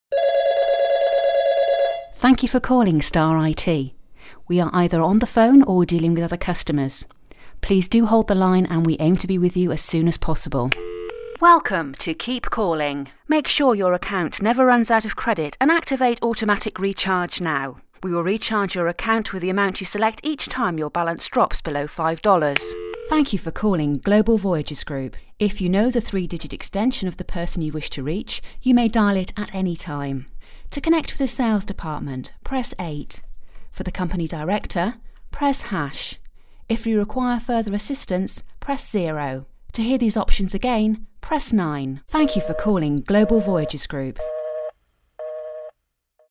englisch (uk)
britisch
Sprechprobe: Sonstiges (Muttersprache):